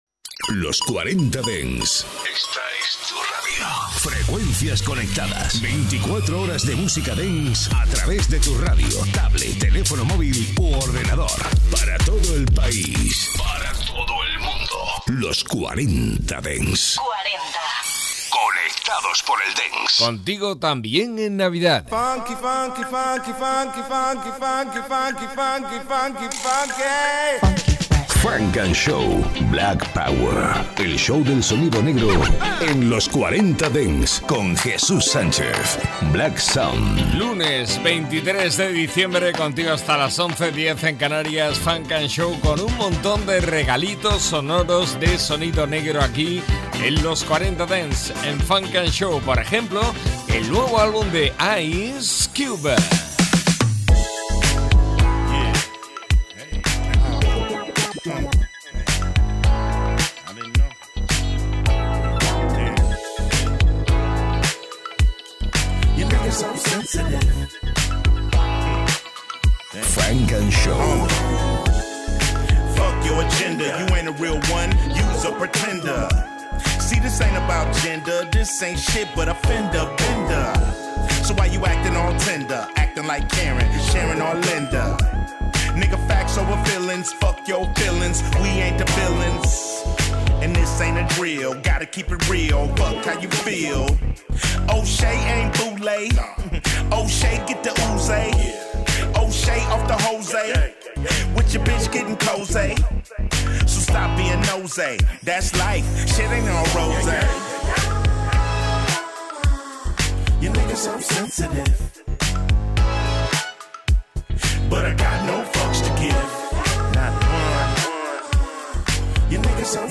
Lo mejor del Techno, Melodic Techno, Prog House y todas las novedades electrónicas.